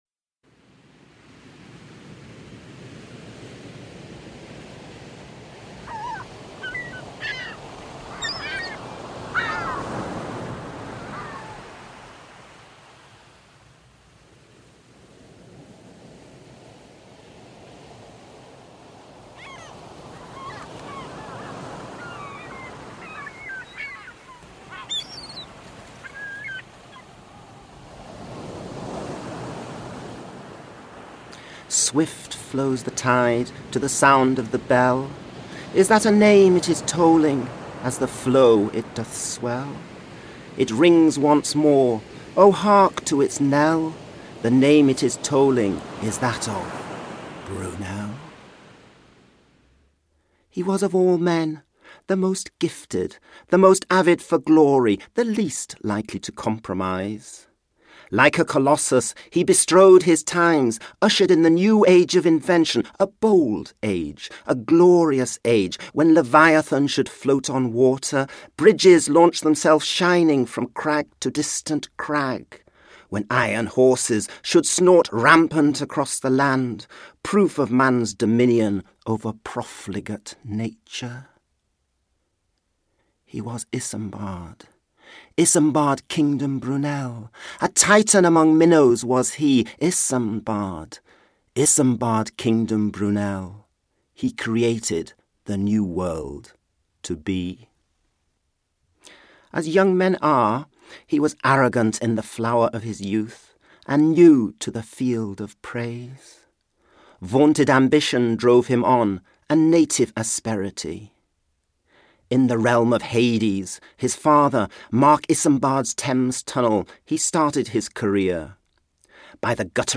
ISAMBARD! THE EPIC POEM is written in epic verse form with ballad inserts. There are five sections to the epic, each corresponding to five phases in Brunel’s life.